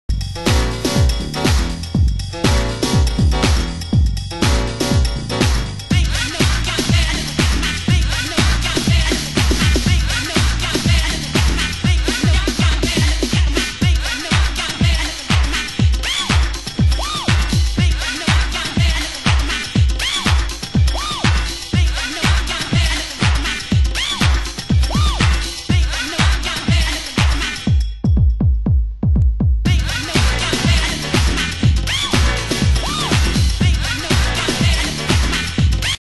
野太いボトム、短いホーンのフレーズが繰り返すBLACKJAZZハウス！